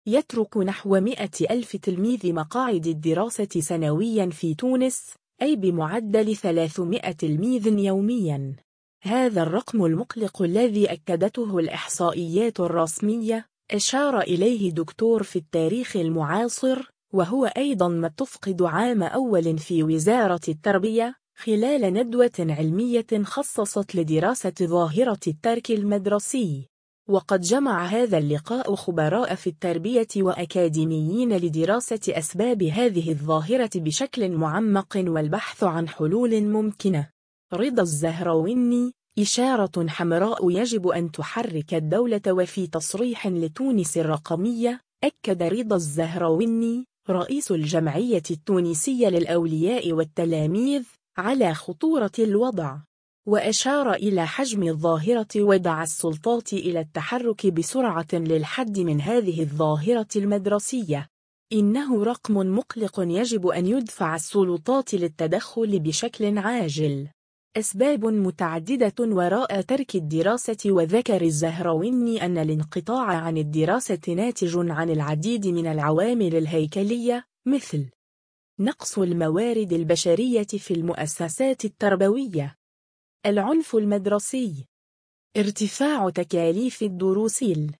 وفي تصريح لــ تونس الرقمية